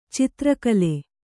♪ citra kale